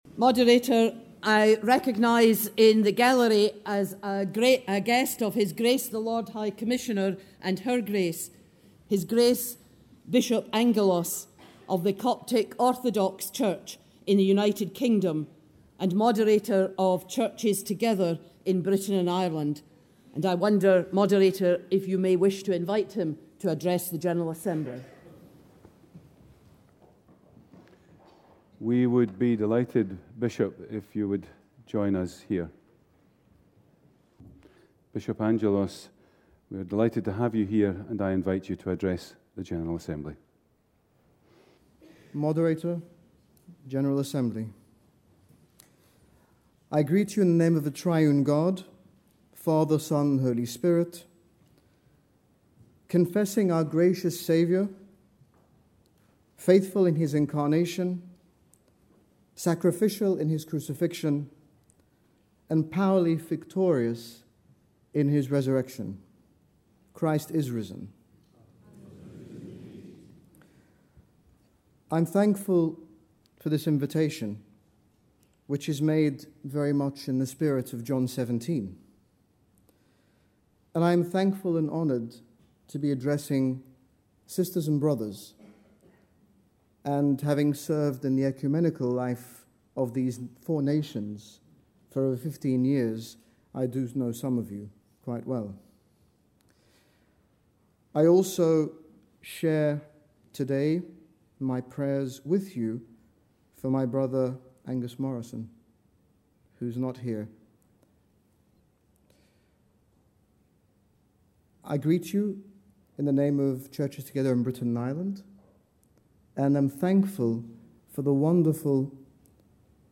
His Grace Bishop Angaelos, General Bishop of the Coptic Orthodox Church in the United Kingdom addressed the Church of Scotland General Assembly on 23 May 2014 speaking about Christian witness in Egypt from both a historical perspective and in light of the current situation.
Church of Scotland General Assembly Address.mp3